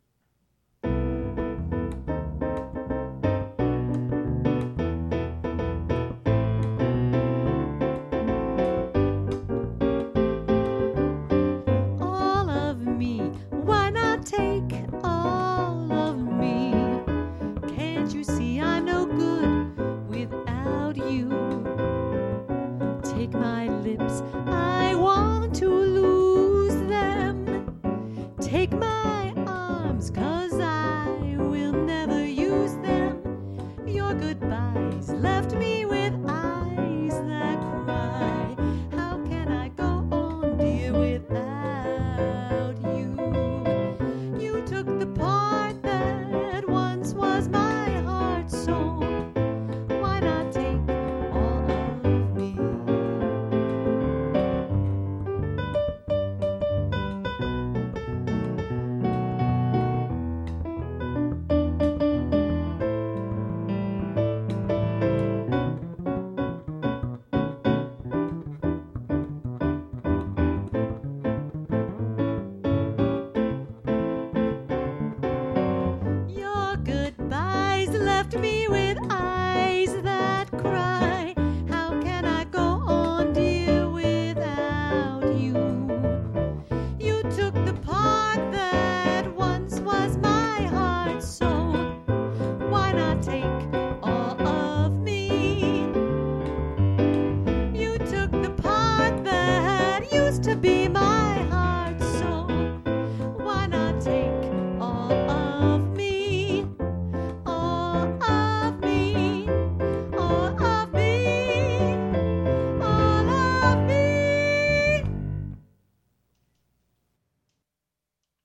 perform music that blends musical theater and jazz traditions in fresh ways. Originating in the stage and screen musicals of Gershwin, Porter, Kern, Rogers & Hart and more, jazz standards defined popular culture from 1930-1950.
When performed by the area’s best jazz musicians in duos, trios or quartets